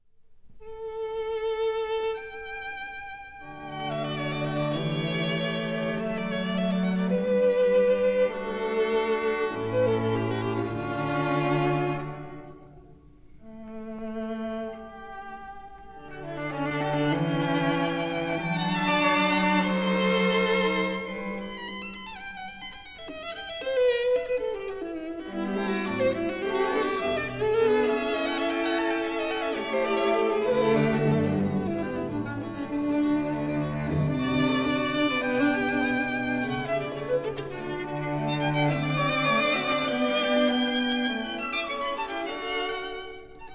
violin
viola
cello
Studios,30th Street, New York City